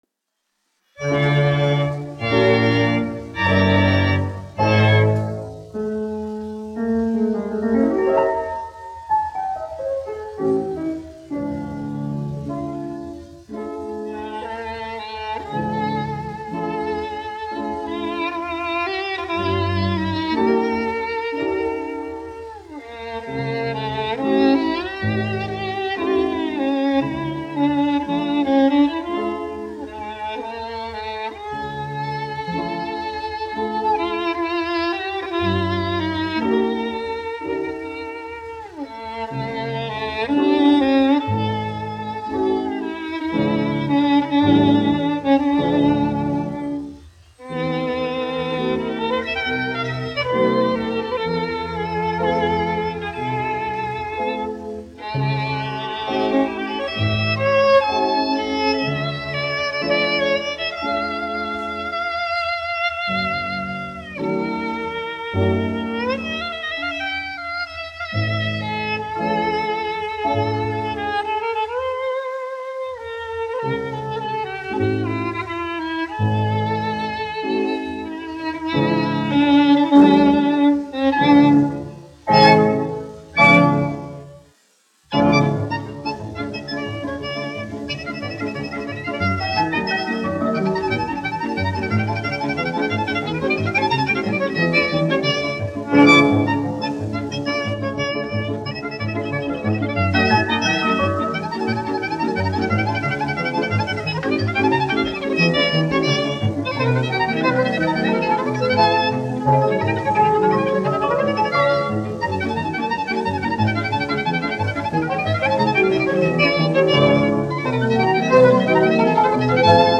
1 skpl. : analogs, 78 apgr/min, mono ; 25 cm
Populārā instrumentālā mūzika
Deju mūzika -- Ungārija
Skaņuplate